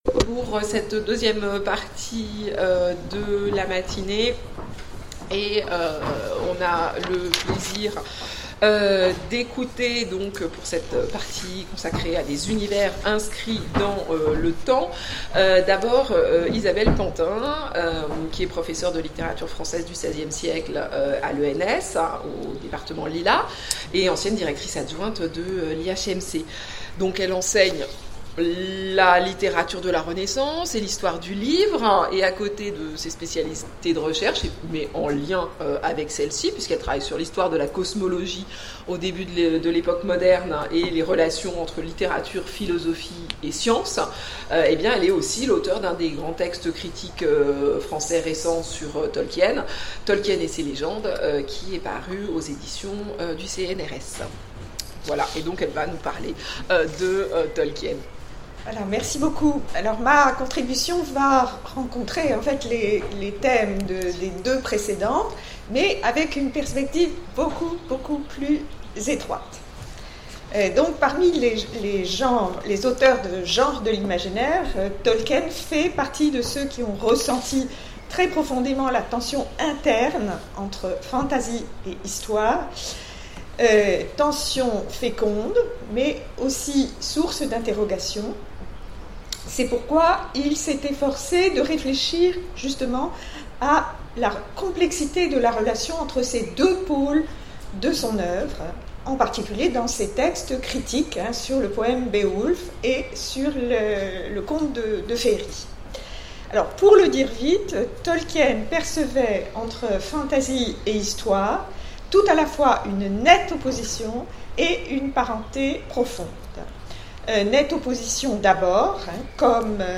Colloque universitaire 2018 : L'histoire au miroir de la légende dans l'œuvre de Tolkien
Conférence